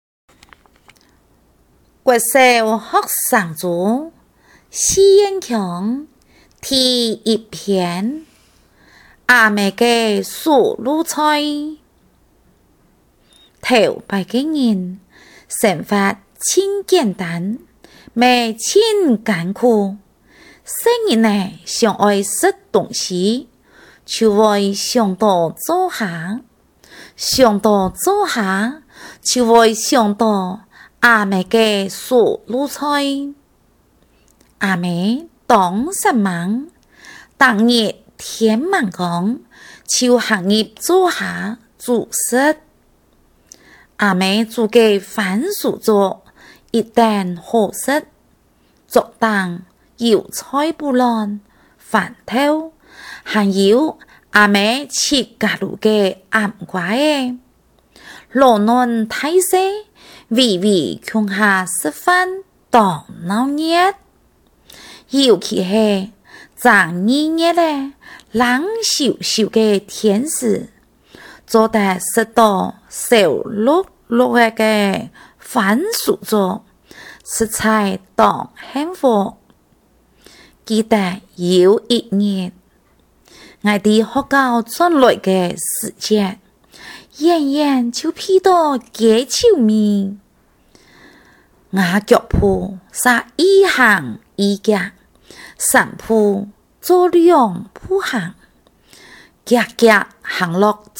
校內多語文競賽---公告客語朗讀(四縣腔)---篇目2篇& 朗誦示範Demo帶